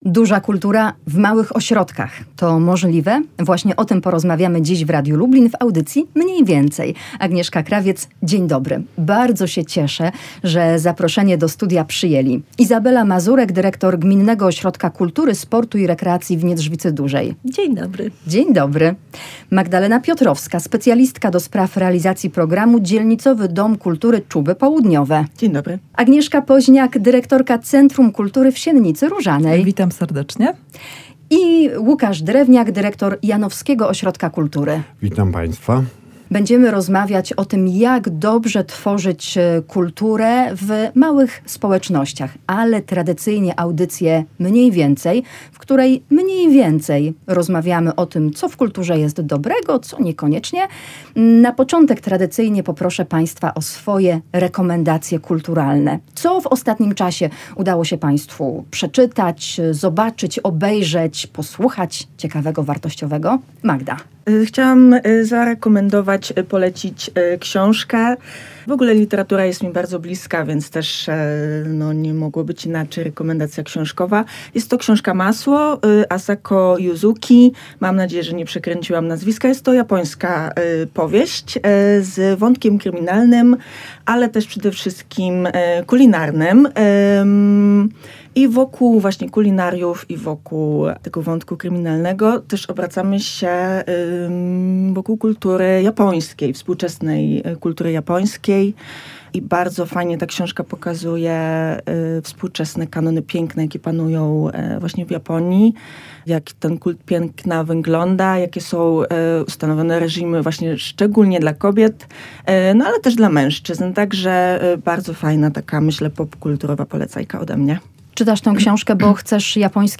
Duża kultura w małych ośrodkach – czy to możliwe? Jaka jest rola ośrodków kultury w mniejszych społecznościach (powiatach, gminach, dzielnicach)? Będziemy o tym dyskutowali w najbliższym programie „Mniej/Więcej”.